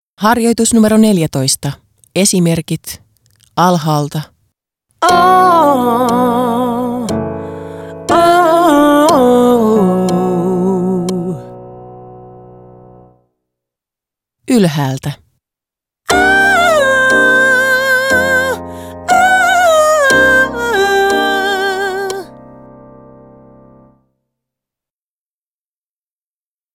27. Esimerkki 14, melismaharjoitus
27-Esimerkki-14-melismaharjoitus.m4a